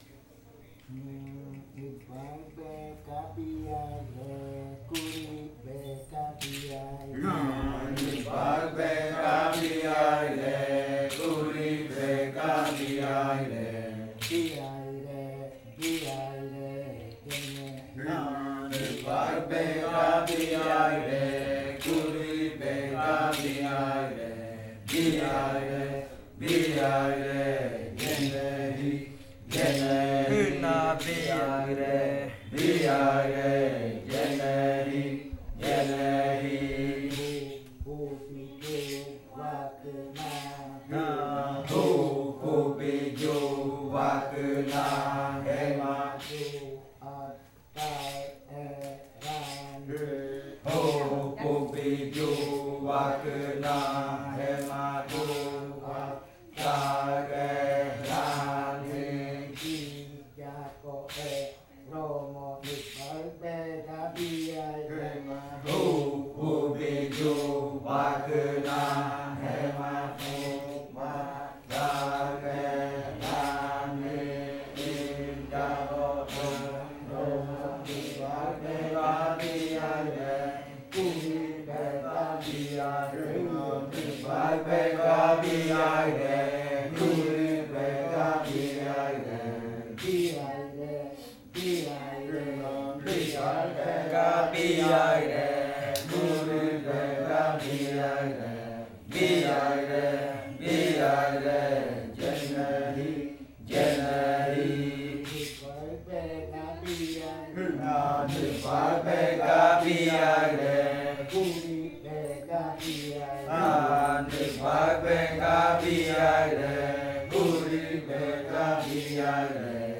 Canto de la variante muruikɨ
Leticia, Amazonas
con el grupo de cantores sentado en Nokaido.